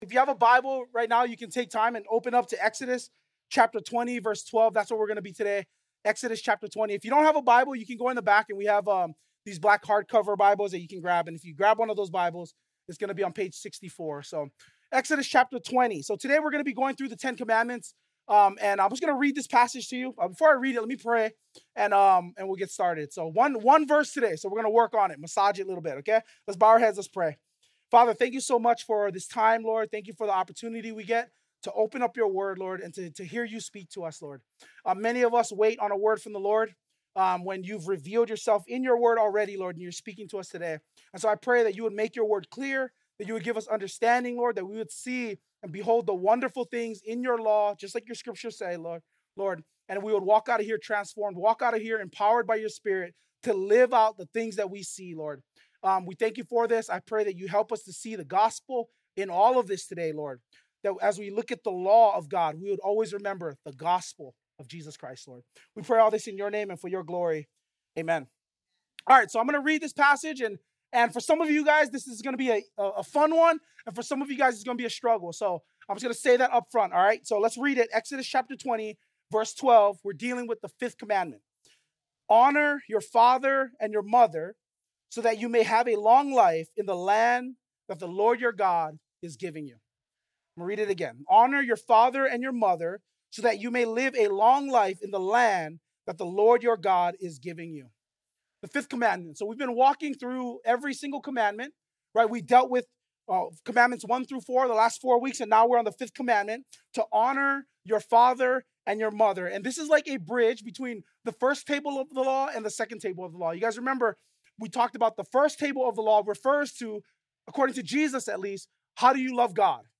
2025 Freed to Bless Your Family Preacher